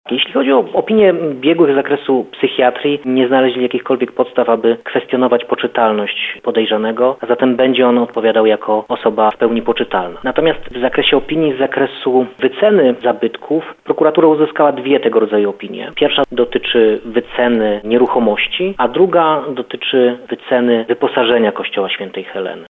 – Prokuratura ma już opinie ekspertów, które były potrzebne w toku postępowania – mówi prokurator rejonowy w Nowym Sączu Bartosz Gorzula.